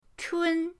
chun1.mp3